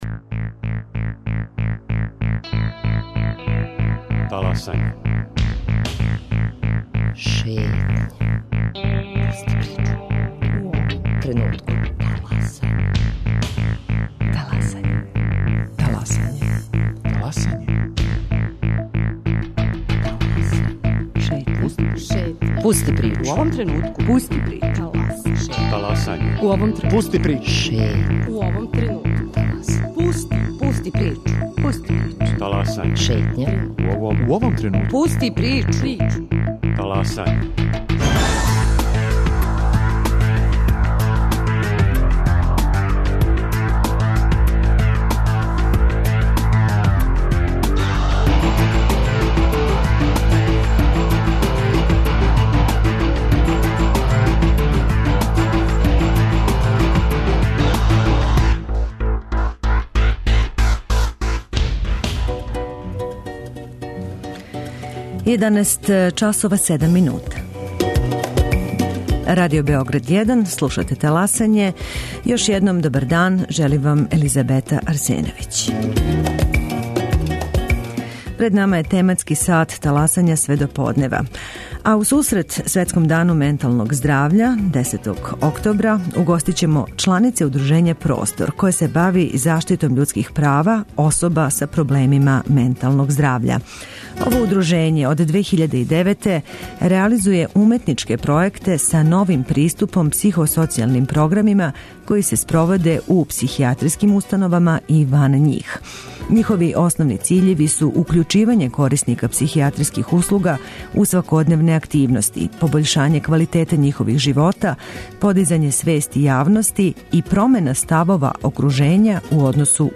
У сусрет Светском дану менталног здравља, 10. октобра, угостићемо чланице удружења 'Простор' које се бави заштитом људских права особа са проблемима менталног здравља.